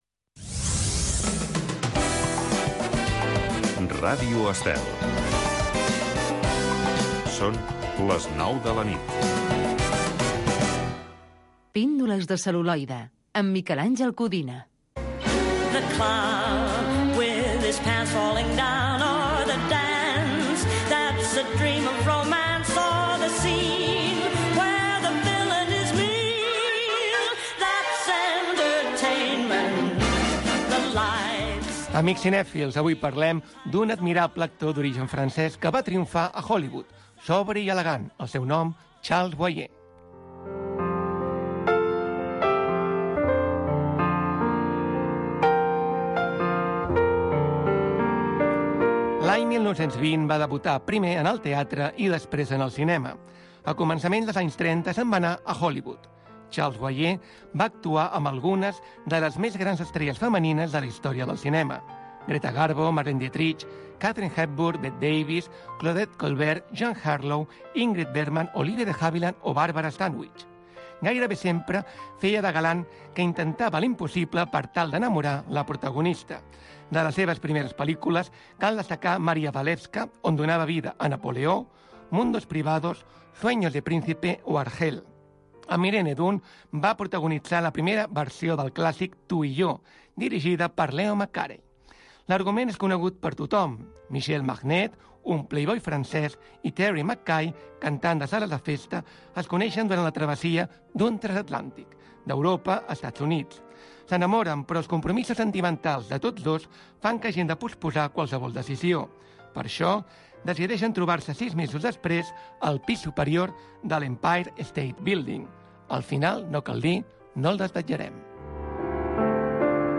Oh la la, la musique. Programa de música francesa.